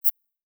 Holographic UI Sounds 27.wav